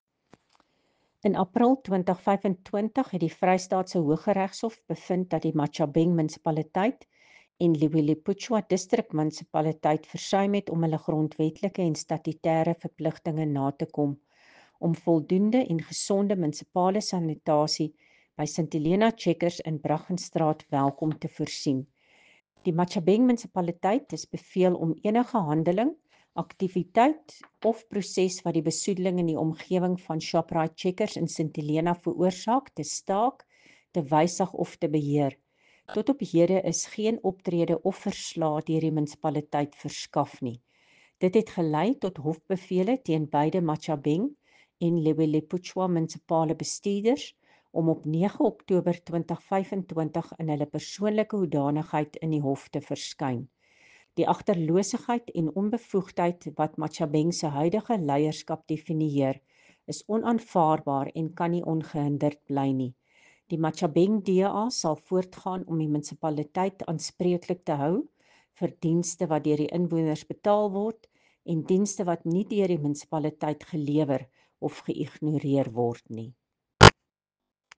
Afrikaans soundbites by Cllr Coreen Malherbe and